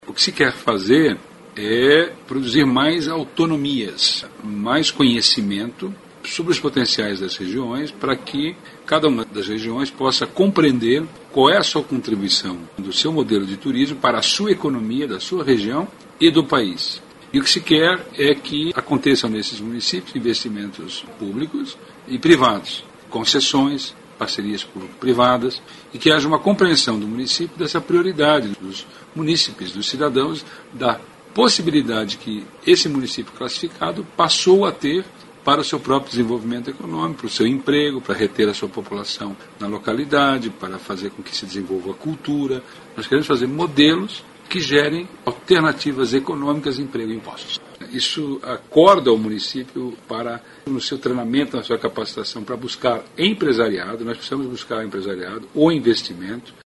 aqui para ouvir declaração do secretário Vinicius Lummertz sobre a importância da regionalização do turismo.